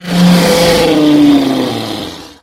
На этой странице собраны разнообразные звуки Змея Горыныча: от грозного рыка до зловещего шипения.
Змей Горыныч рычит от боли